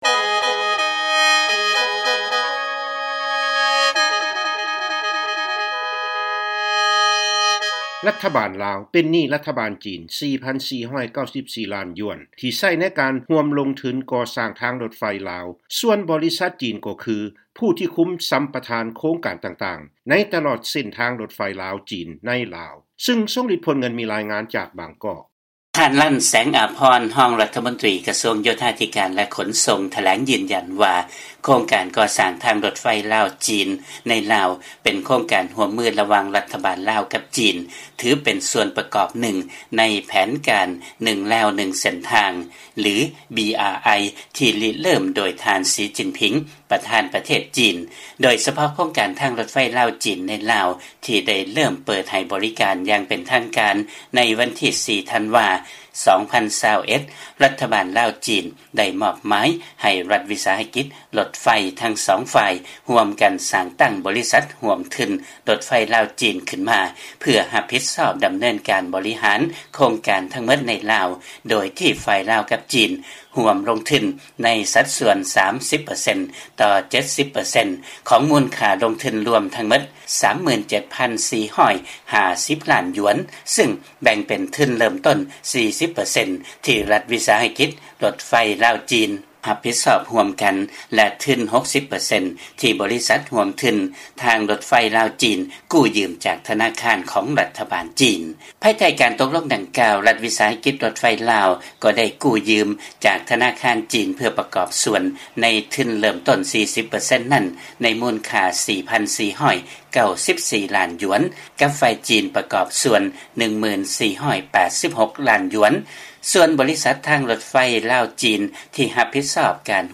ເຊີນຟັງລາຍງານ ລັດຖະບານລາວ ເປັນໜີ້ລັດຖະບານຈີນ 4,494 ລ້ານຢວນ ທີ່ໃຊ້ໃນການຮ່ວມລົງທຶນກໍ່ສ້າງທາງລົດໄຟ ຄວາມໄວສູງ ລາວ-ຈີນ